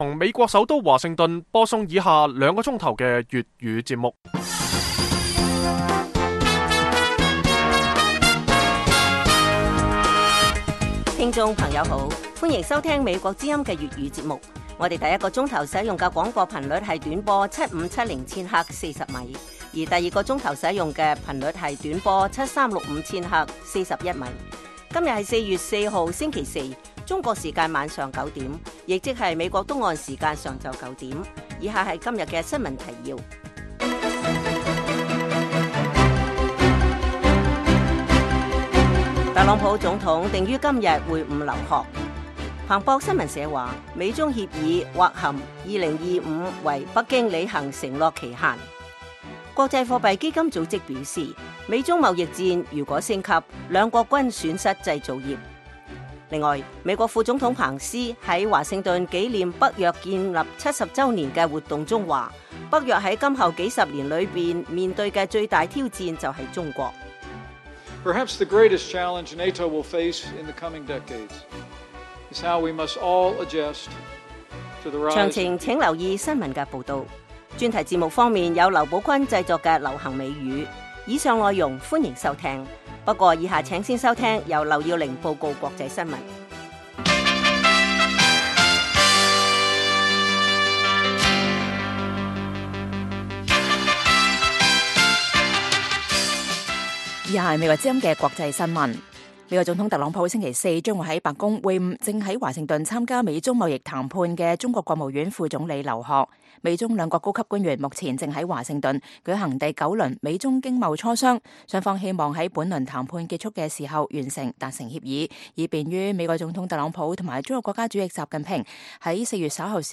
粵語新聞 晚上9-10點
北京時間每晚9－10點 (1300-1400 UTC)粵語廣播節目。內容包括國際新聞、時事經緯和英語教學。